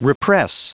Prononciation du mot repress en anglais (fichier audio)
Prononciation du mot : repress